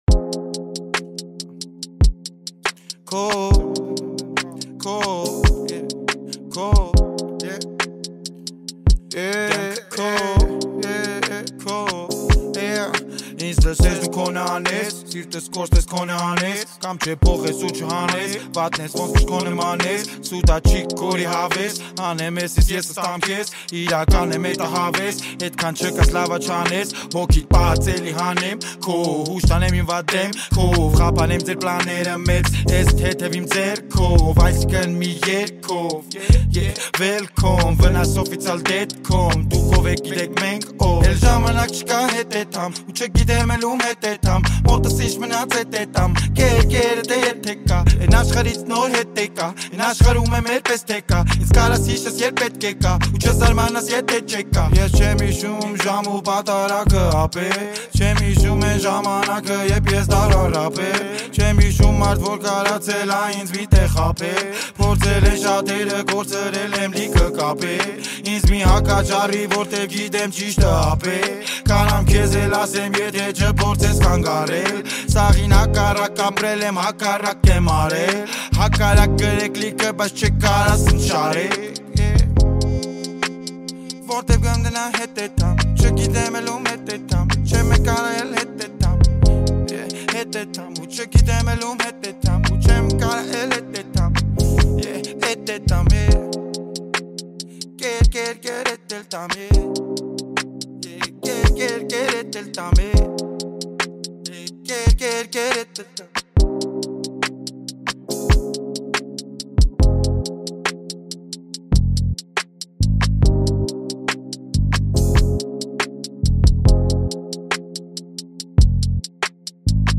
Haykakan rap